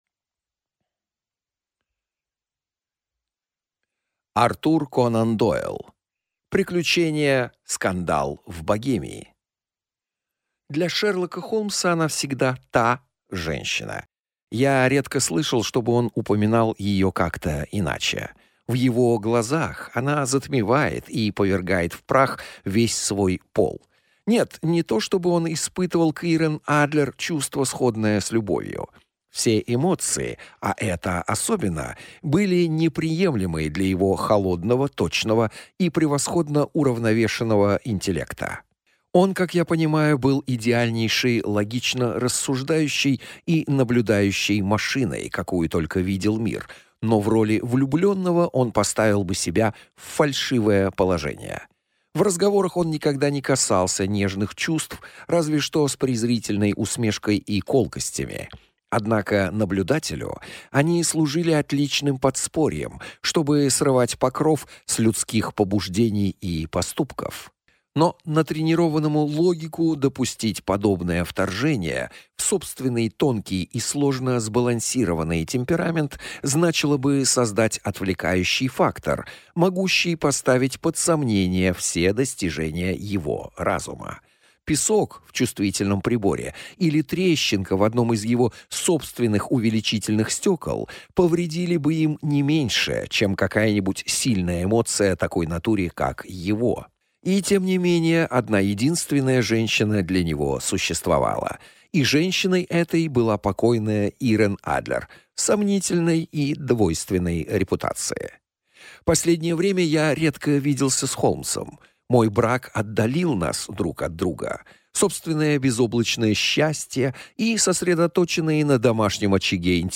Аудиокнига Приключение «Скандал в Богемии» | Библиотека аудиокниг